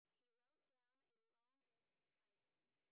sp11_train_snr0.wav